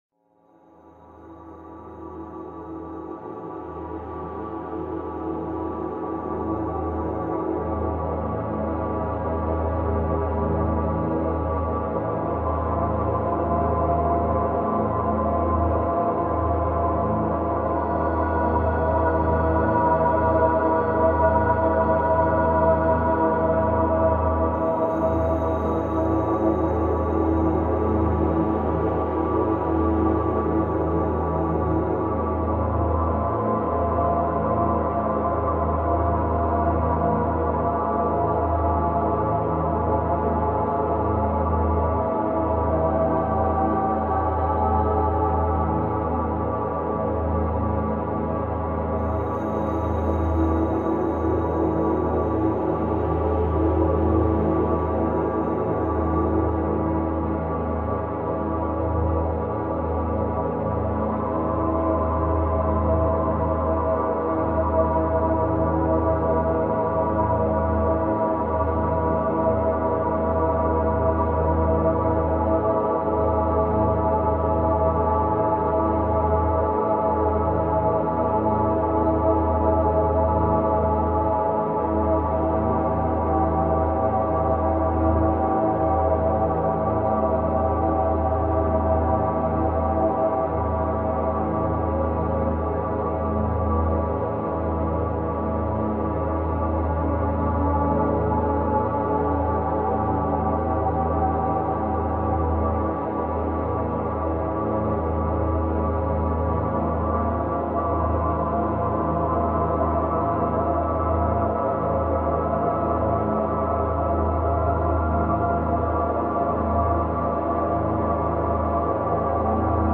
Pain Relief – 174 Hz Solfeggio for Chronic Pain and Stress